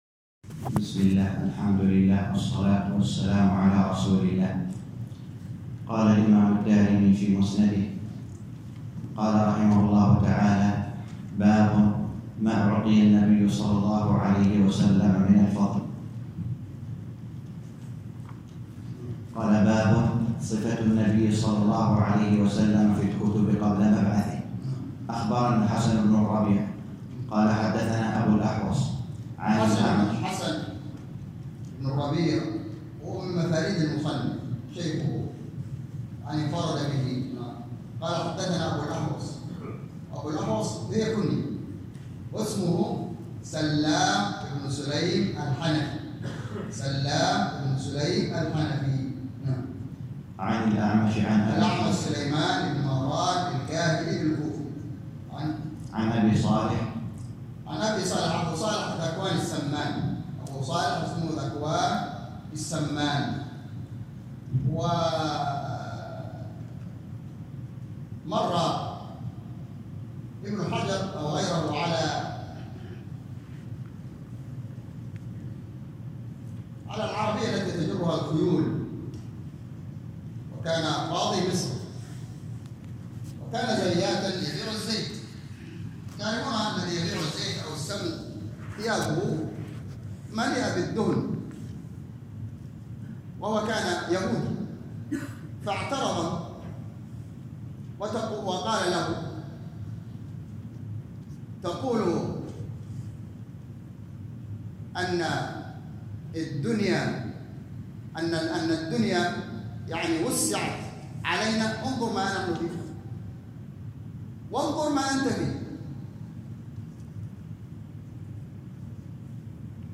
الدرس الثالث - شرح سنن الدارمي الباب الثاني _ 3